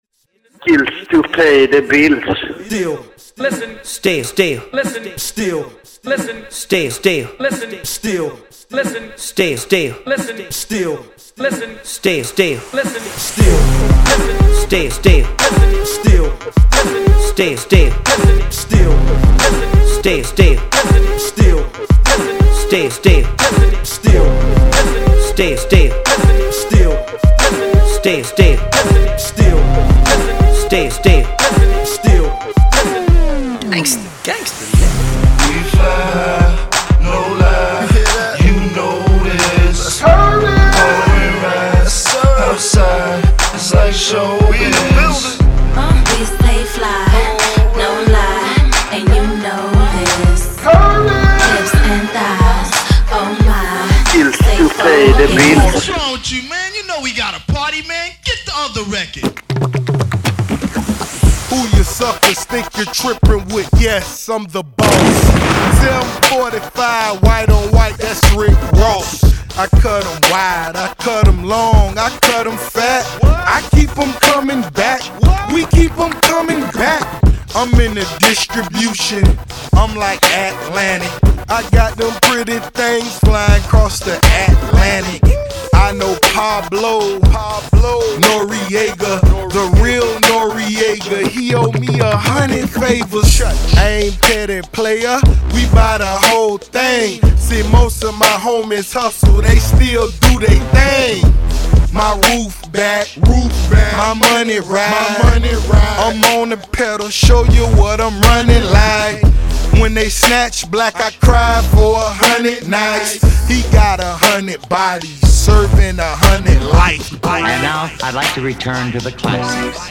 Labels: Mixes